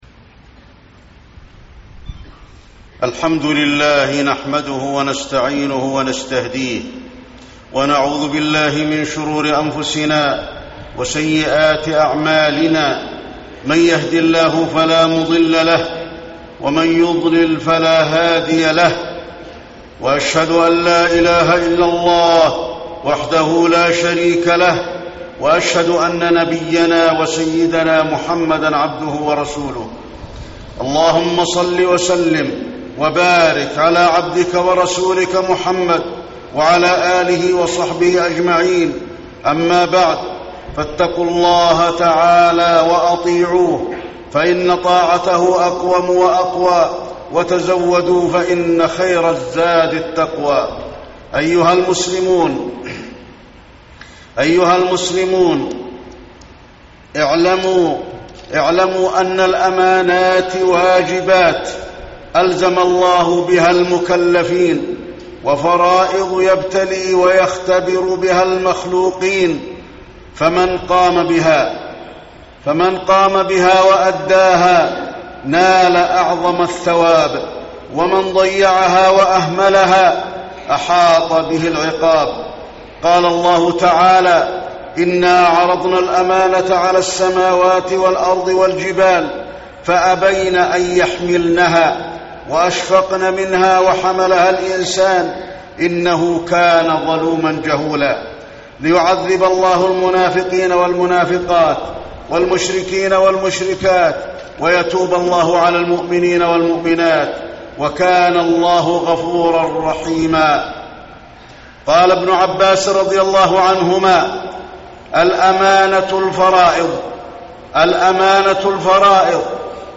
تاريخ النشر ٢٤ رجب ١٤٣٠ هـ المكان: المسجد النبوي الشيخ: فضيلة الشيخ د. علي بن عبدالرحمن الحذيفي فضيلة الشيخ د. علي بن عبدالرحمن الحذيفي أمانة الله